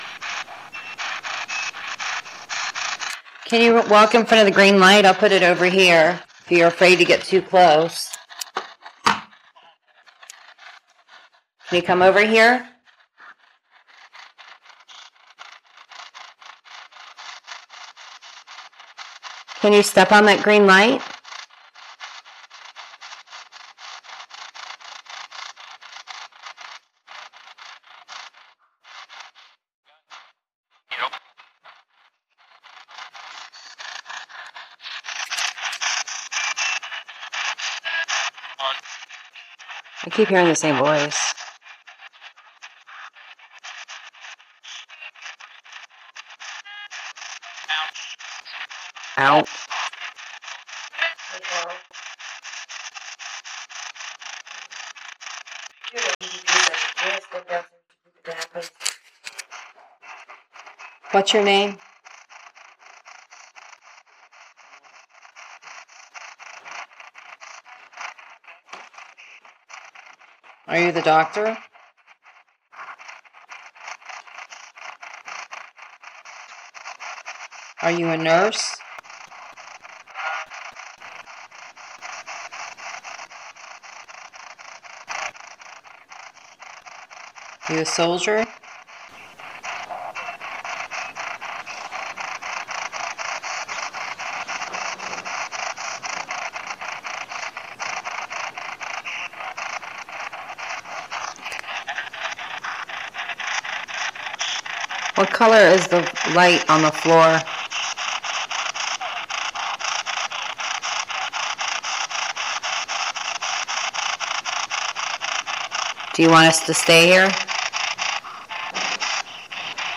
EVP
I am adding this 9 minute clip about 1/2 of it is with the PSB97 and their is a really wild one..
I can break these up into smaller ones, but it gets time consuming breaking theseupinto1 minutes clips, this way you get a better idea of our dialog around the time the recordings happened.